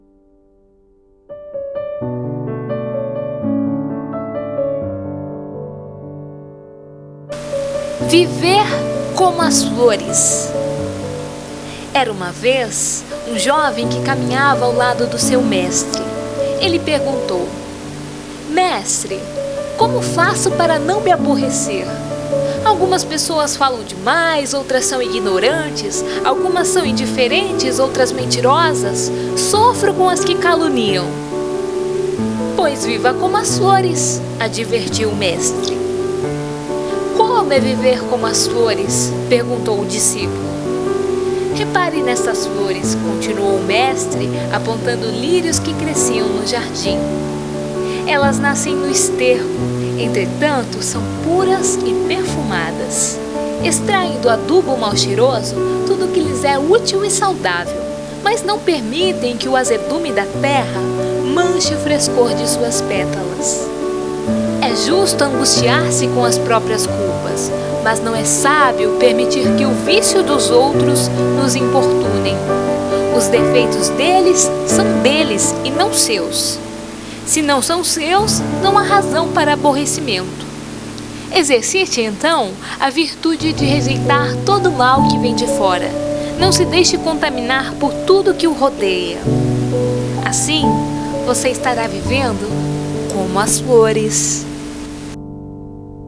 Esta locução é mais uma reflexão de vida. Podemos selecionar as energias que chegam até nós, vamos praticar? :)